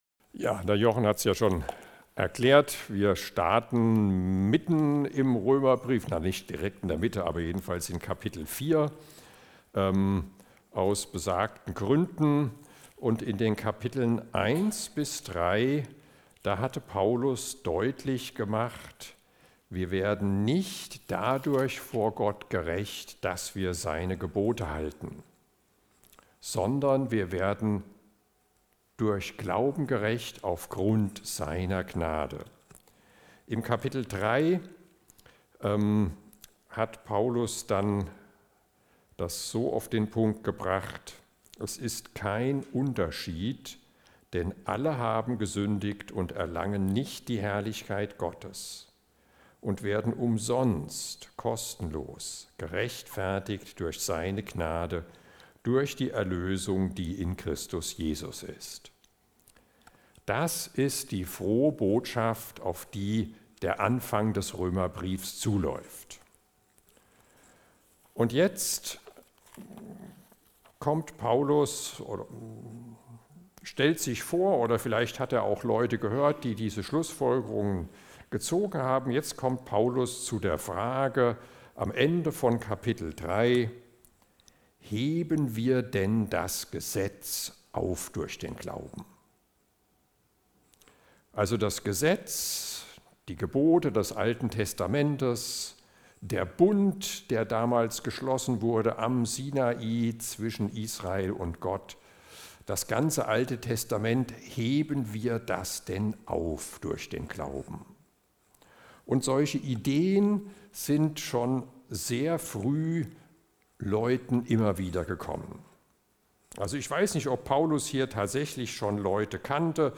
Eröffnungsgottesdienst zu den Bibeltagen 2026